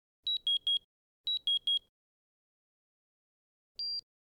Disconnect-Lead-Wires-with-1-event-in-memory
Disconnect-Lead-Wires-with-1-event-in-memory.mp3